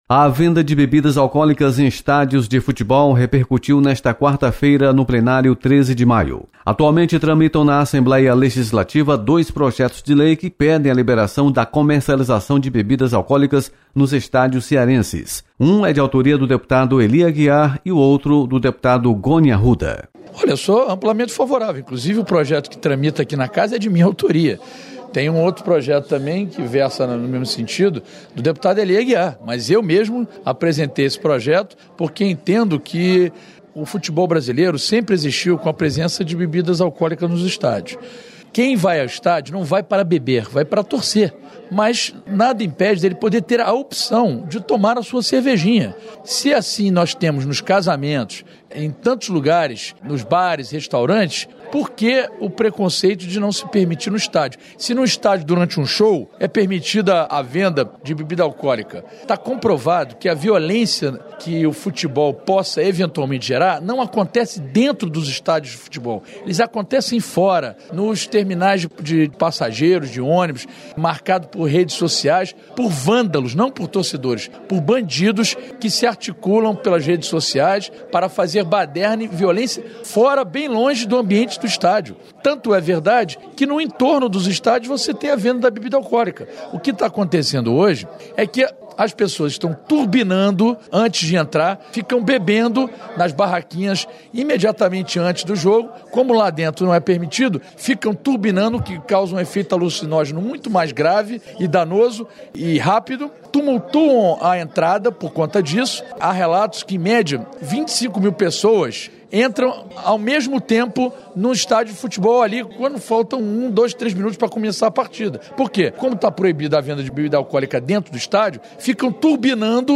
Parlamentares defendem liberação de bebidas alcoólicas nos estádios cearenses. Repórter